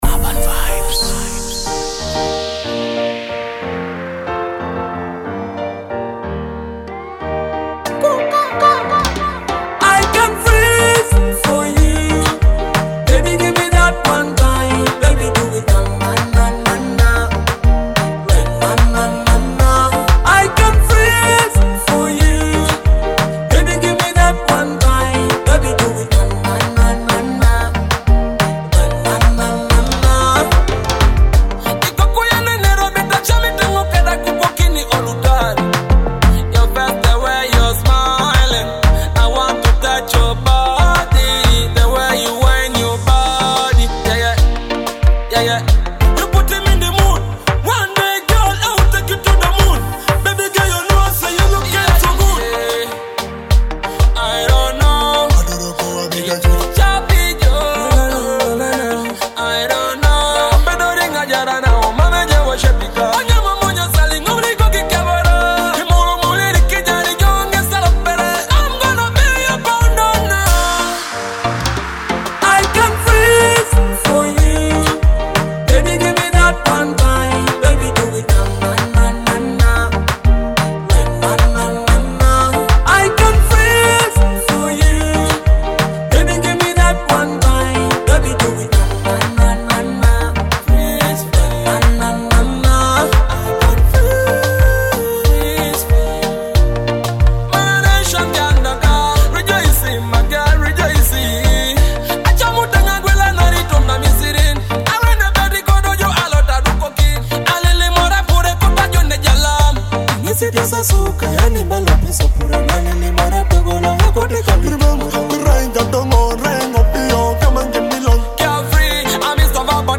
sets the perfect mood with its laid-back yet upbeat energy.